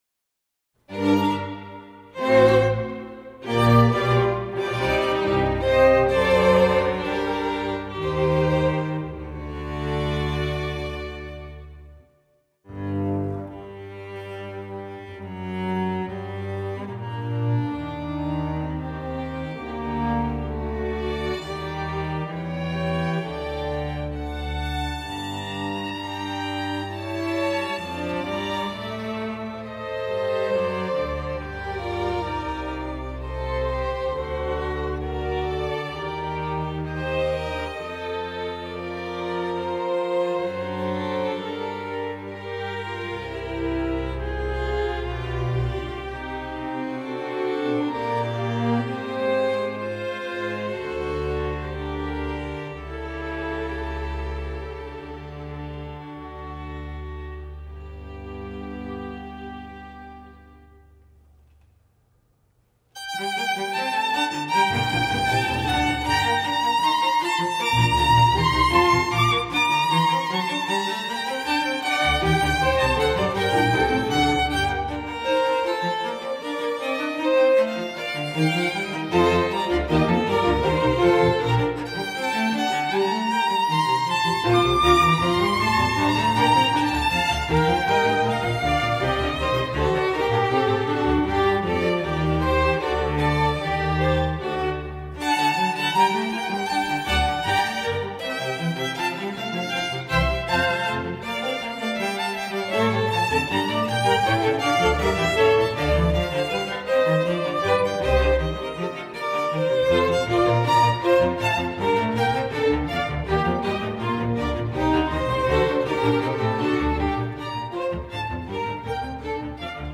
for 4 Violins, Viola, 2 Cellos and Bass